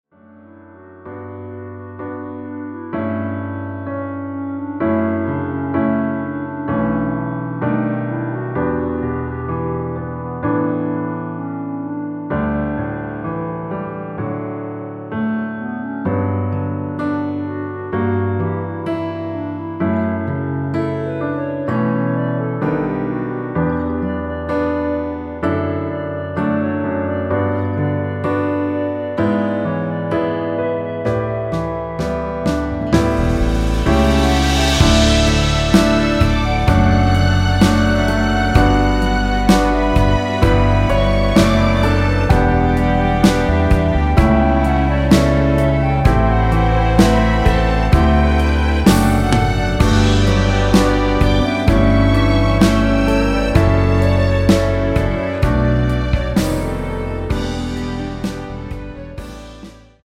1절후 후렴으로 진행되게 편곡 되었습니다.(본문의 가사 참조)
원키에서(-1)내린 (1절+후렴)멜로디 포함된 MR입니다.
앞부분30초, 뒷부분30초씩 편집해서 올려 드리고 있습니다.